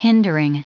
Prononciation du mot hindering en anglais (fichier audio)
Prononciation du mot : hindering